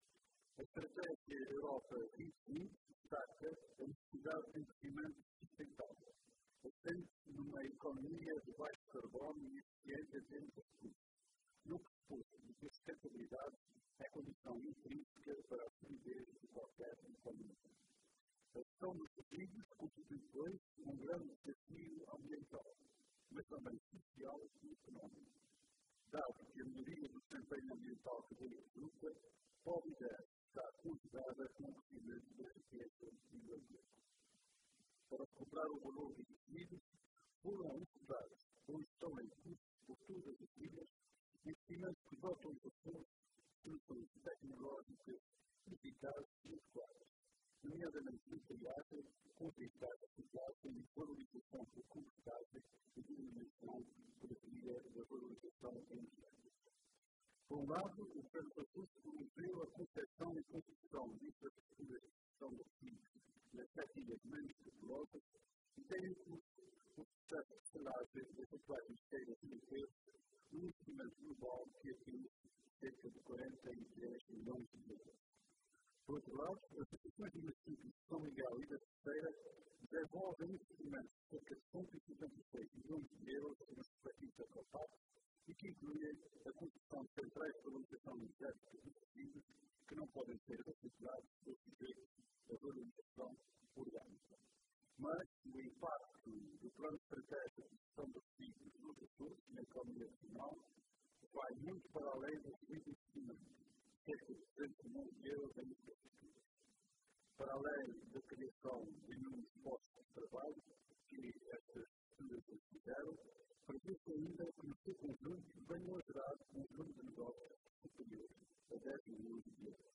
“O impacto da implementação do PEGRA na economia regional vai muito para além do referido investimento de cerca de 200 milhões de euros em infraestruturas”, frisou o Secretário Regional da Agricultura e Ambiente numa intervenção nesta cerimónia, que contou com a presença do Presidente do Governo dos Açores, Vasco Cordeiro.